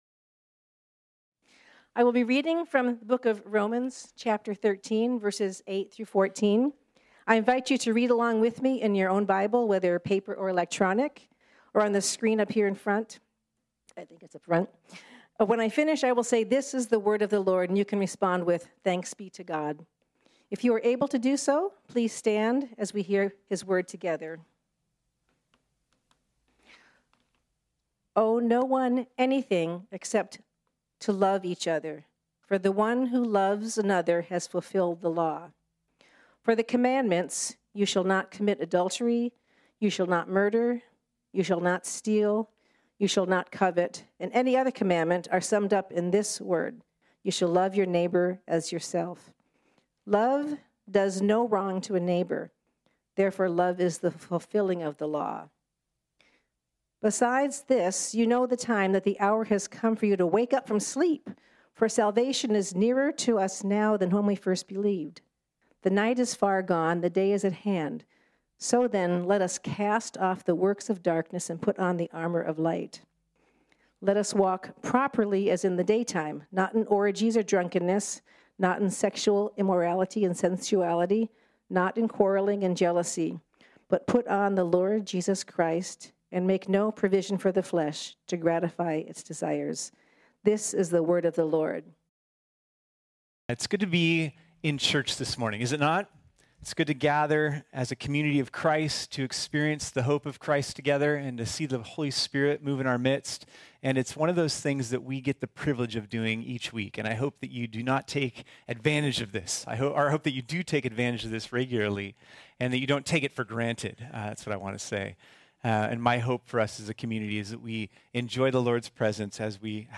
This sermon was originally preached on Sunday, July 25, 2021.